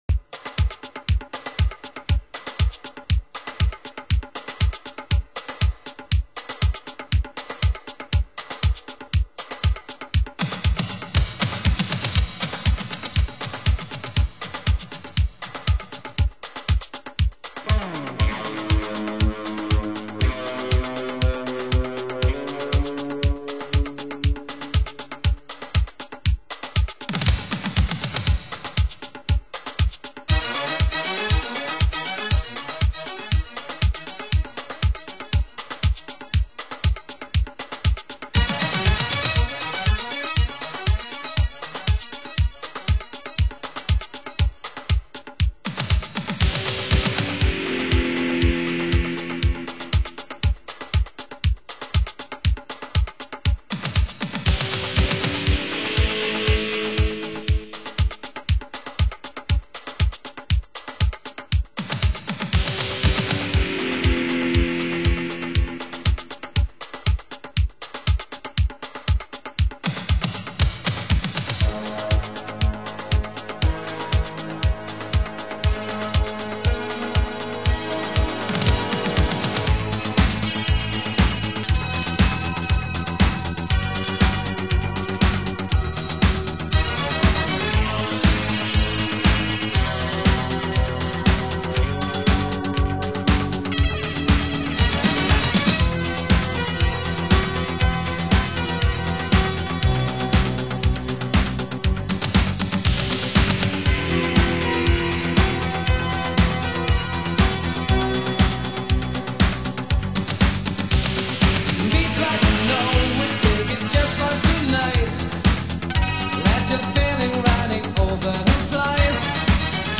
Genre: Italo-Disco